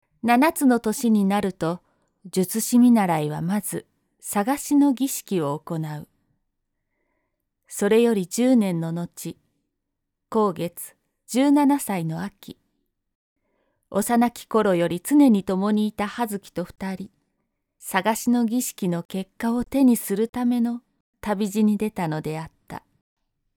語　−かたり−
【サンプルボイス】